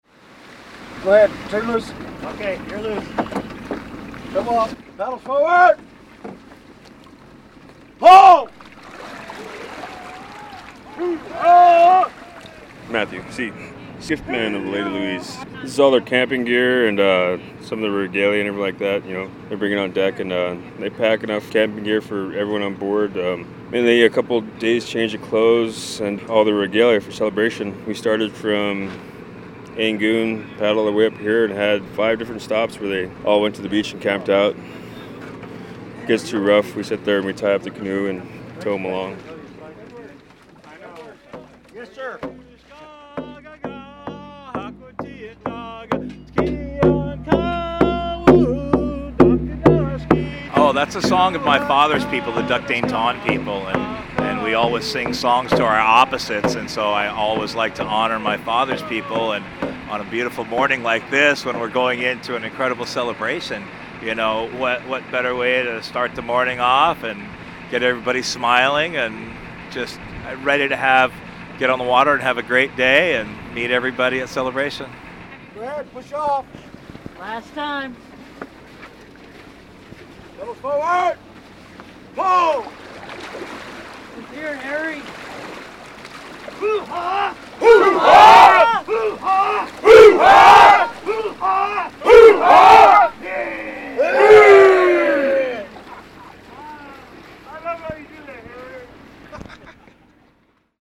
Audio Postcard: One People Canoe Society lands in Douglas for Celebration